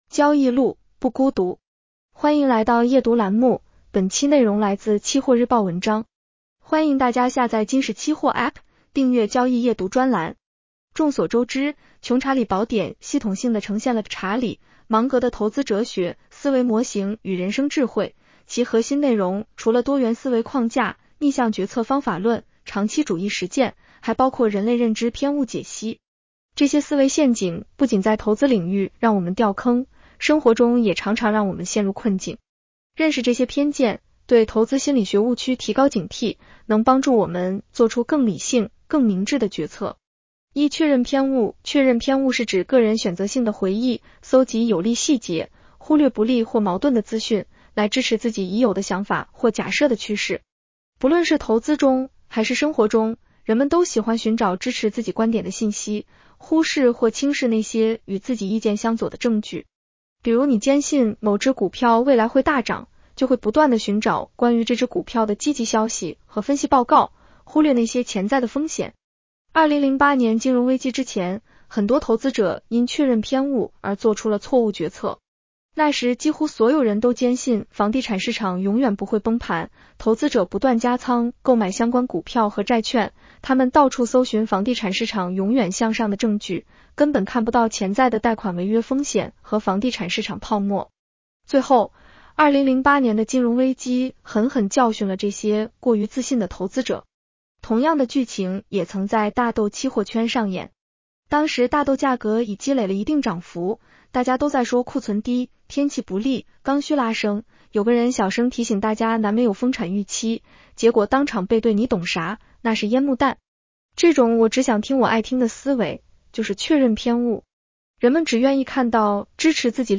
女声普通话版 下载mp3 众所周知，《穷查理宝典》系统性地呈现了查理·芒格的投资哲学、思维模型与人生智慧，其核心内容除了多元思维框架、逆向决策方法论、长期主义实践，还包括人类认知偏误解析。